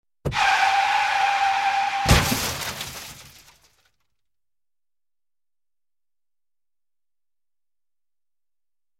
Звуки тормоза автомобиля
На этой странице собрана коллекция звуков тормозов автомобилей: от резкого визга шин до плавного скрежета.
Визг тормозов автомобиля и итог — ДТП